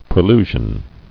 [pre·lu·sion]